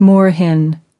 Transcription and pronunciation of the word "moorhen" in British and American variants.